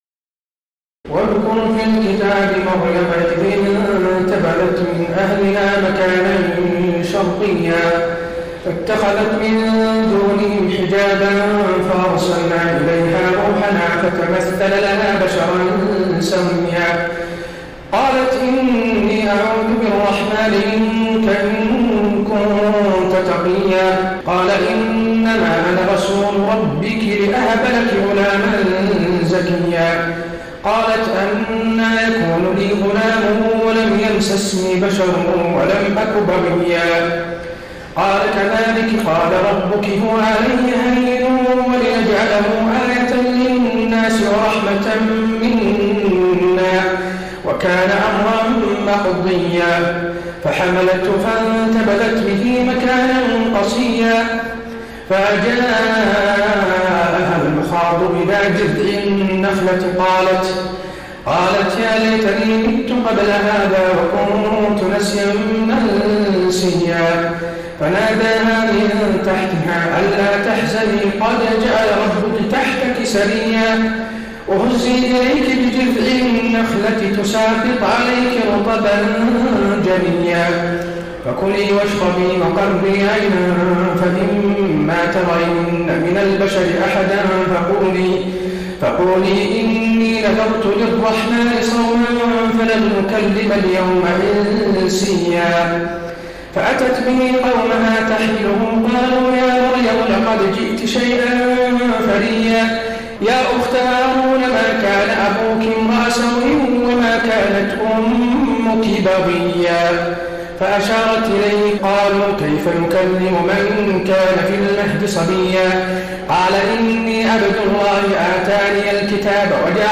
تراويح الليلة الخامسة عشر رمضان 1433هـ من سورتي مريم (16-98) وطه (1-112) Taraweeh 15 st night Ramadan 1433H from Surah Maryam and Taa-Haa > تراويح الحرم النبوي عام 1433 🕌 > التراويح - تلاوات الحرمين